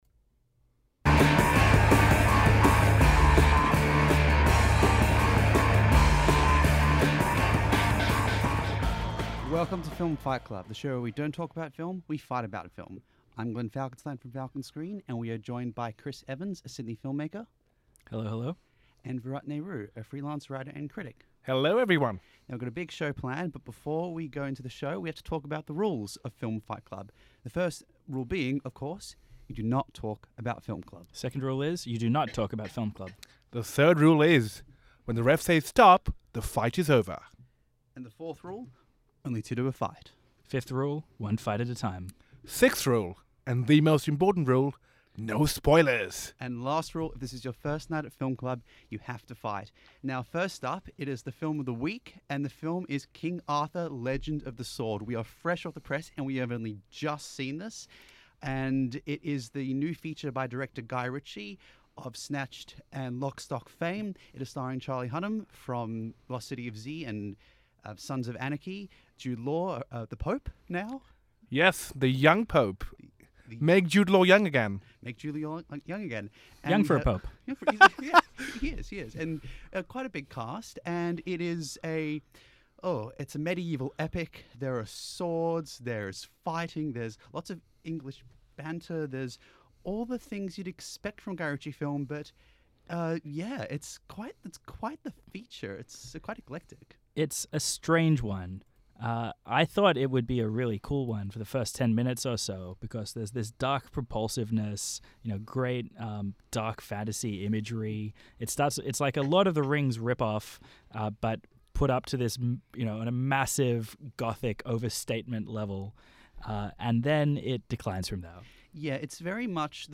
three guys who really, really love film